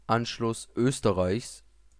pronunciation (bantuan·maklumat)), adalah pengilhakan negara Austria kedalam Jerman Nazi pada Mac 1938.[2]
De-Anschluss_Österreichs.ogg